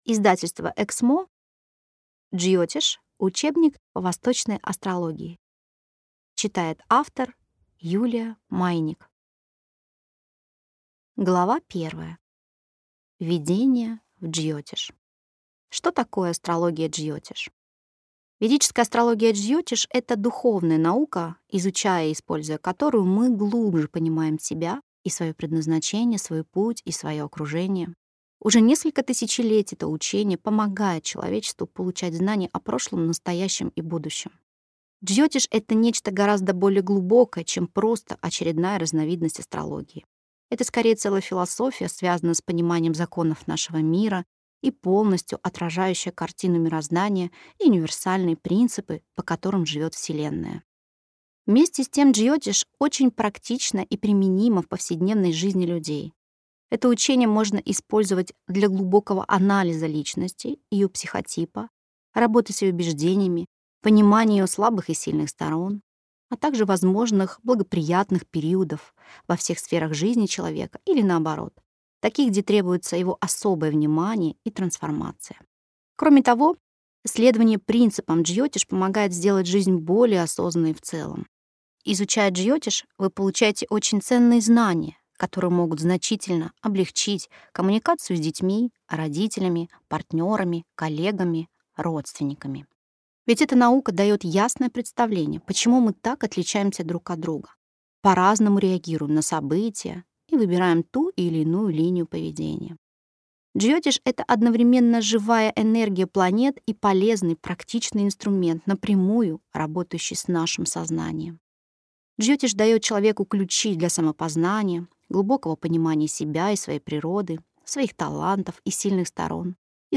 Аудиокнига Джйотиш. Учебник по Восточной Астрологии | Библиотека аудиокниг